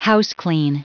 Prononciation du mot houseclean en anglais (fichier audio)
Prononciation du mot : houseclean